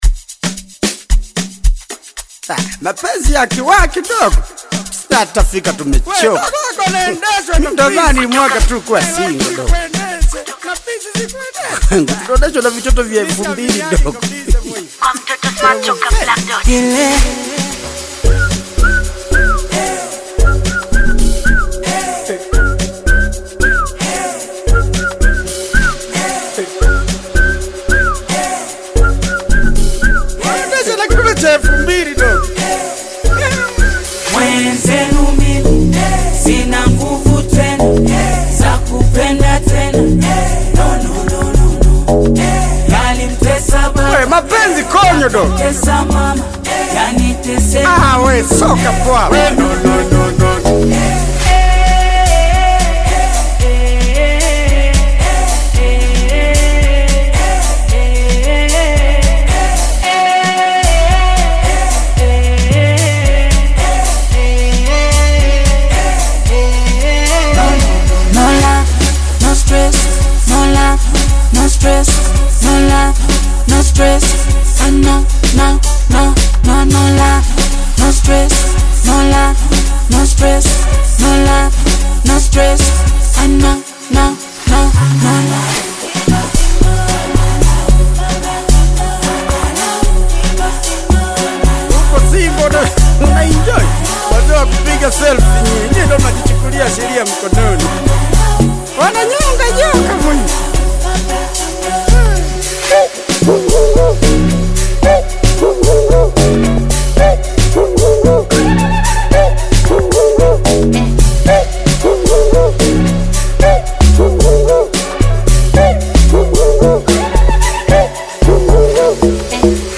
Tanzanian Bongo Flava
Bongo Flava